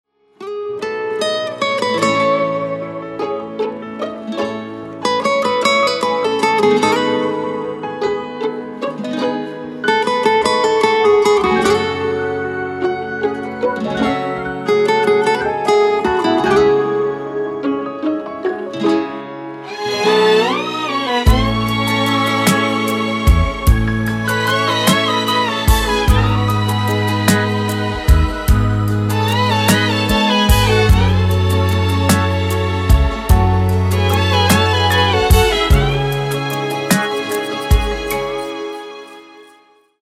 بی کلام رینگتون موبایل